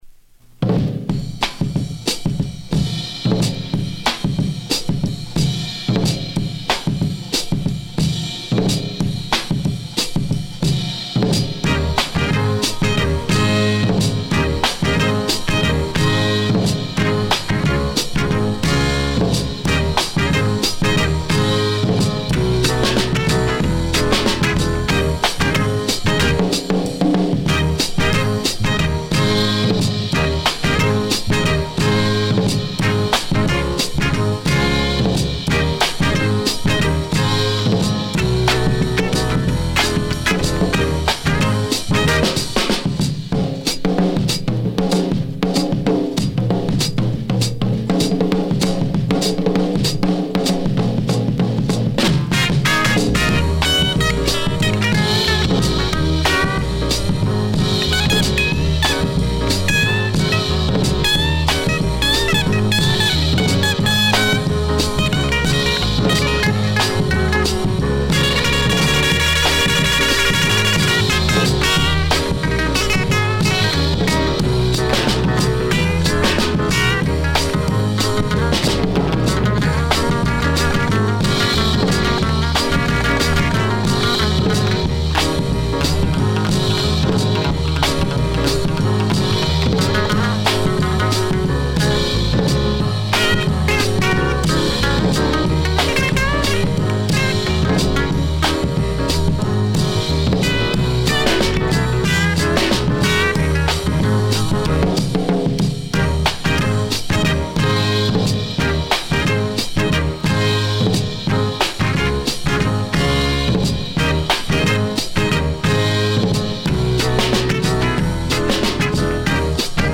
Funk
Side A 試聴はここをクリック ※実物の試聴音源を再生状態の目安にお役立てください。